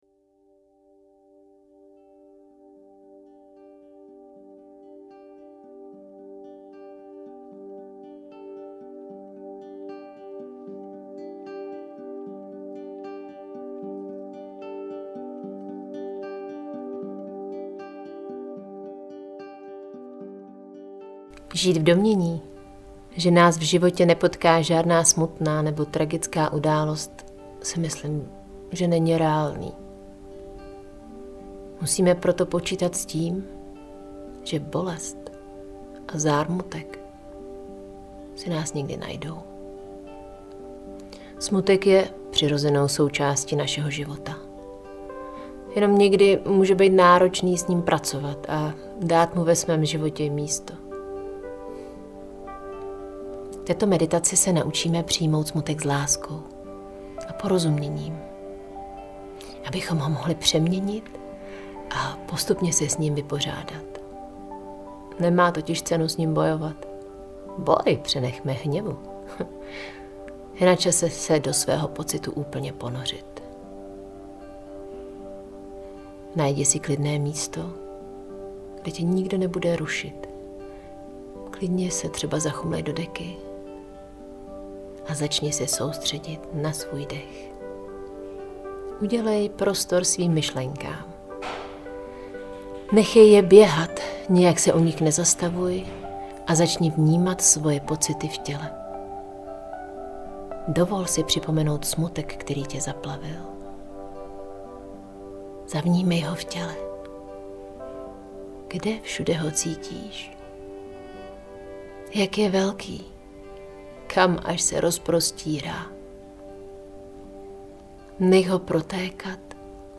meditace-vodopad-smutek.mp3